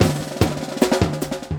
LOOP39SD06-L.wav